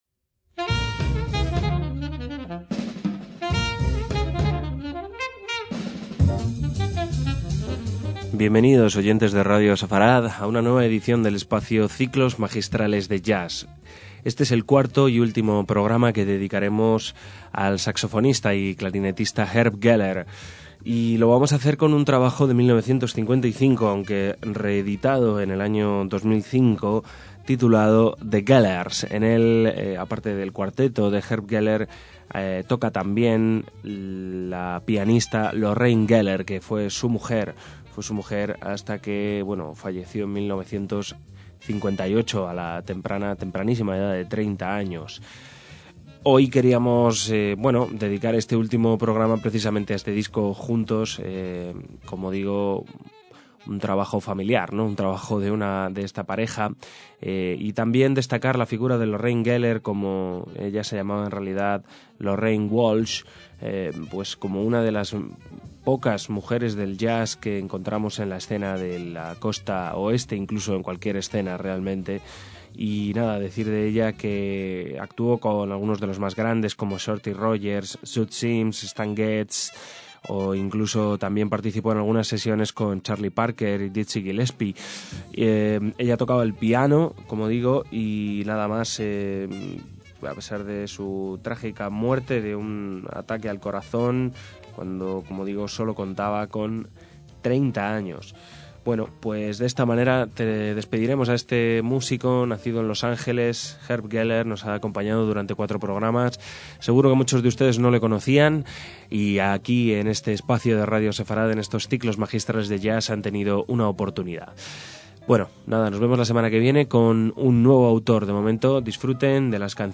CICLOS MAGISTRALES DE JAZZ